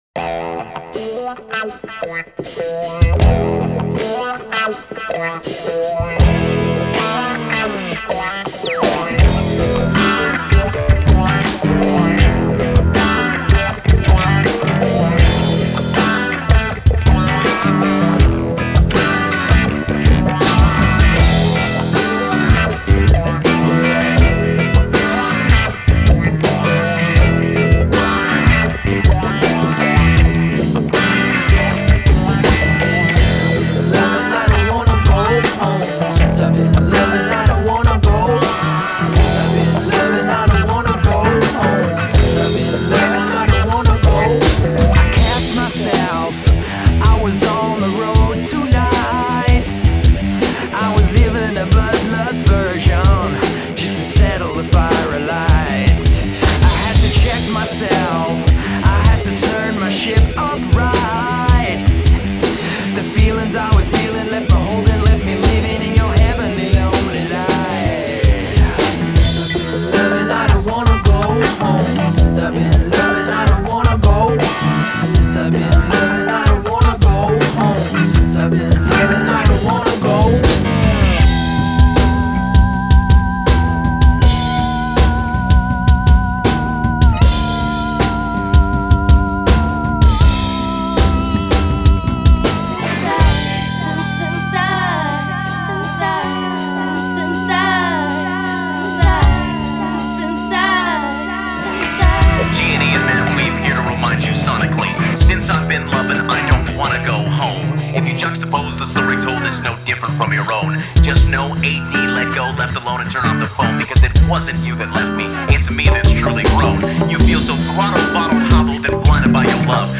folk-alt-indie-rock band